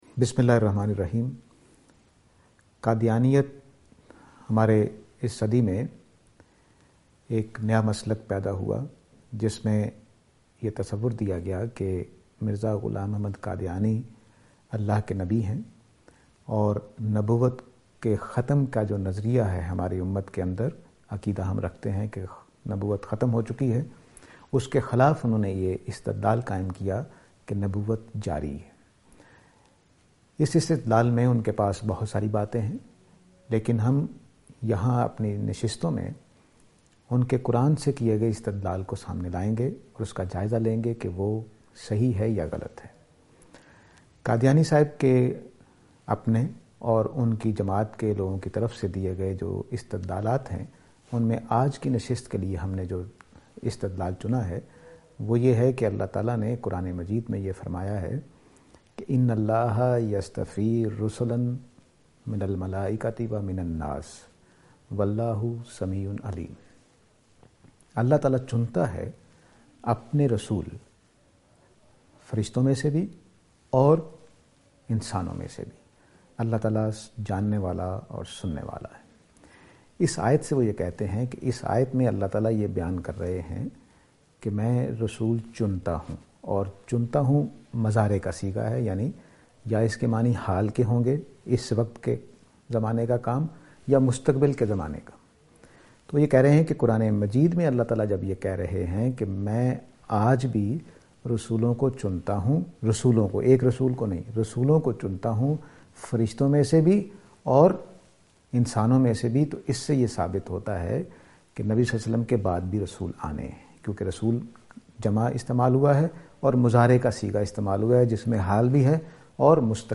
This lecture series will deal with Reviewing Qadiyani Discourse .